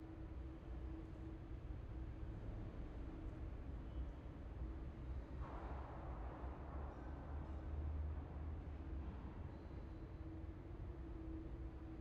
sfx-jfe-amb-loop-1.ogg